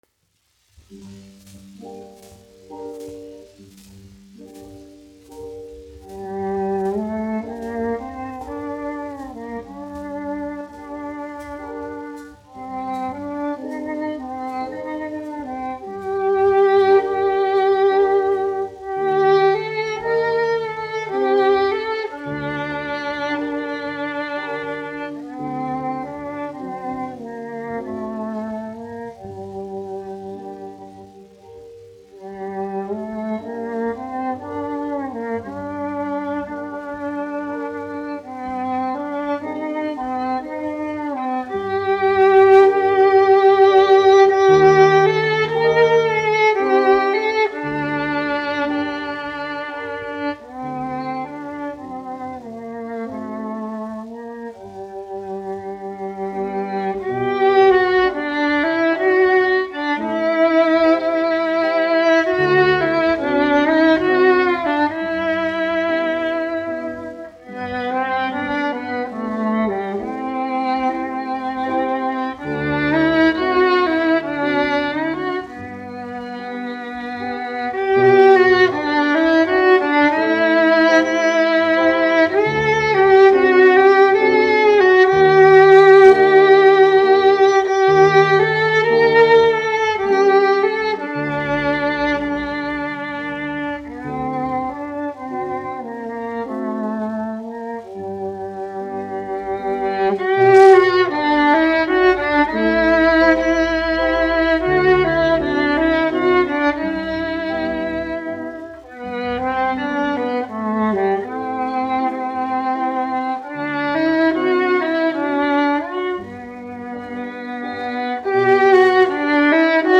1 skpl. : analogs, 78 apgr/min, mono ; 25 cm
Čella un klavieru mūzika, aranžējumi
Latvijas vēsturiskie šellaka skaņuplašu ieraksti (Kolekcija)